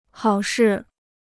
好事 (hào shì): to be meddlesome.